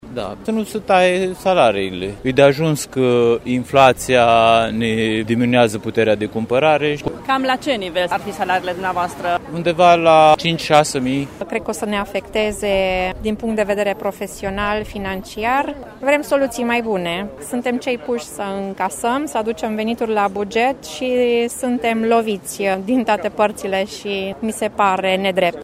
Este mesajul pe care au dorit să-l transmită astăzi angajații din cadrul Administrației Financiare Târgu Mureș care au ieșit în stradă pentru câteva ore într-un protest spontan, nemulțumiți că au fost puși pe ”lista de tăieri” a Guvernului.